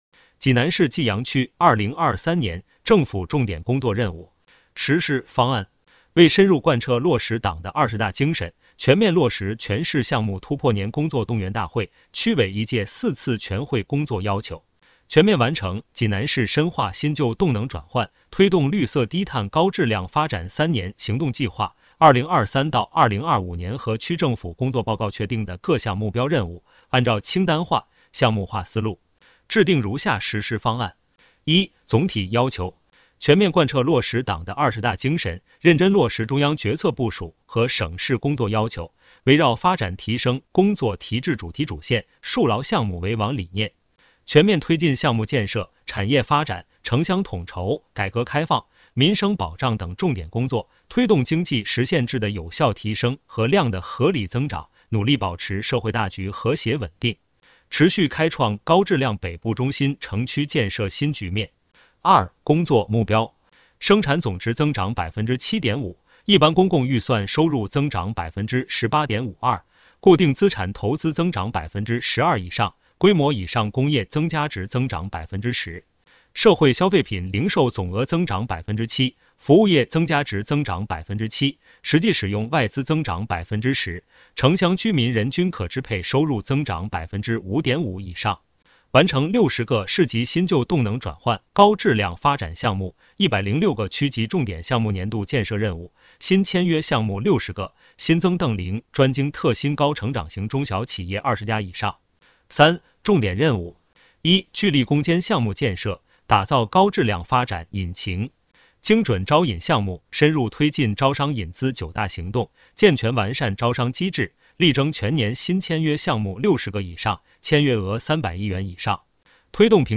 区发展和改革局 - 有声朗读 - 【有声朗读】济南市济阳区2023年政府重点工作任务清单